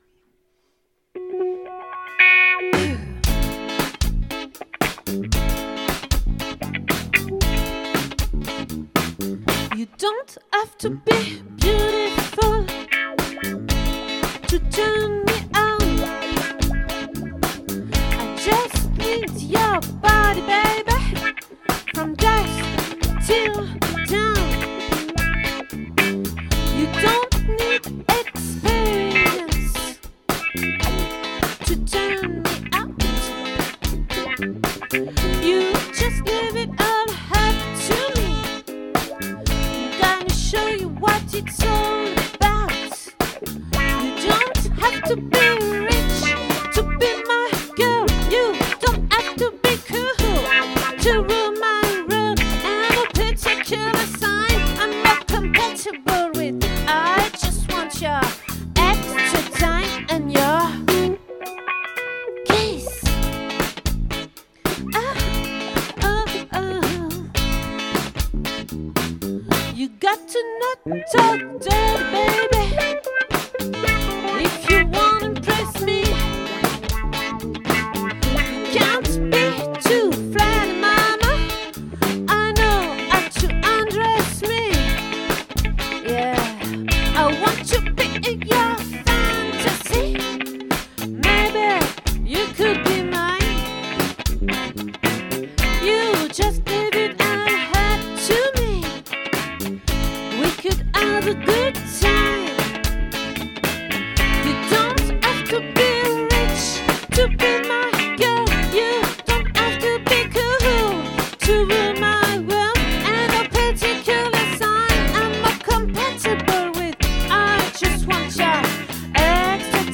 🏠 Accueil Repetitions Records_2022_02_02